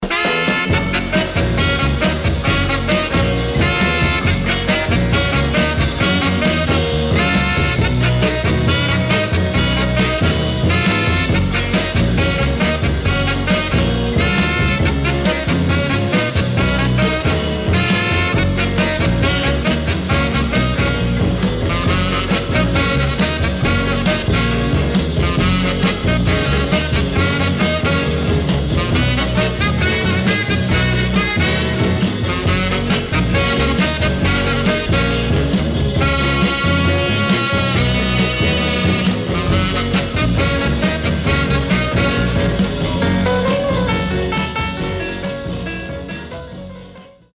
Acetate 5-2-61 Nyc Condition: M-
Mono
R&B Instrumental